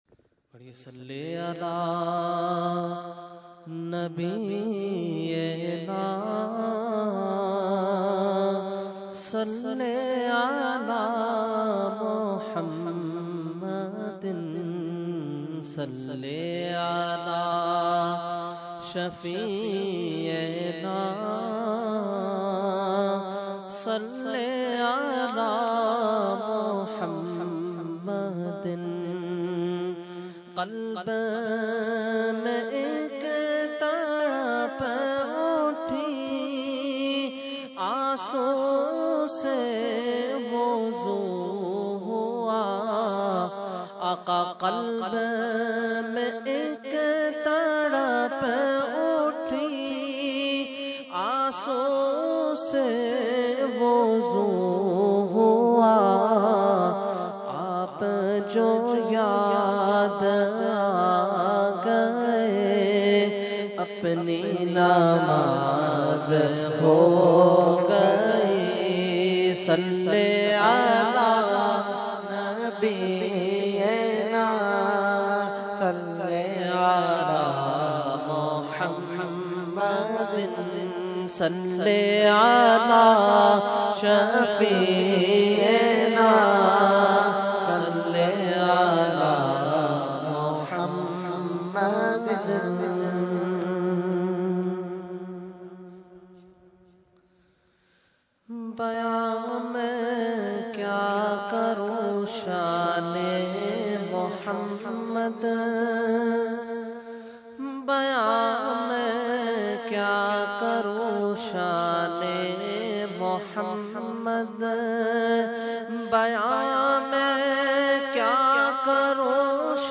Category : Naat | Language : UrduEvent : Mehfil PECHS Society Khi 2015